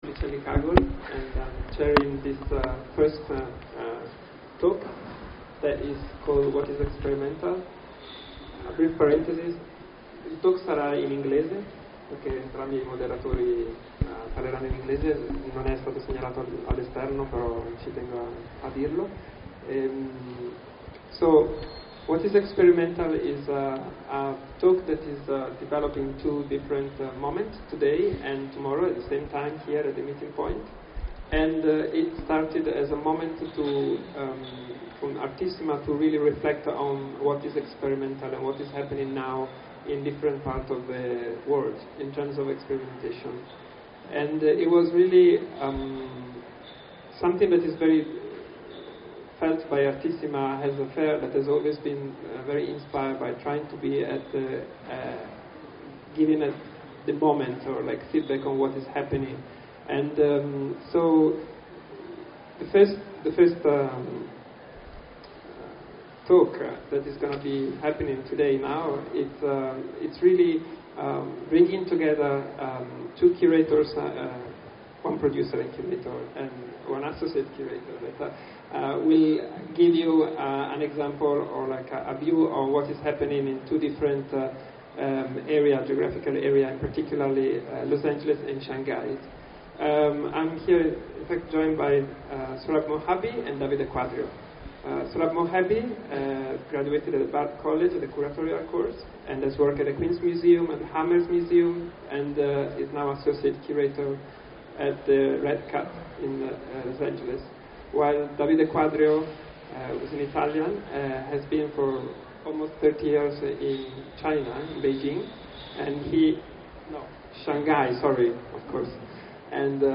La redazione di KABUL magazine ha deciso di rendere disponibili, all’interno del sito, le registrazioni audio di alcuni dei talk di Artissima2016.
Oggi, venerdì 4 novembre, presso il Meeting Point di Artissima, si è conclusa la prima parte del talk.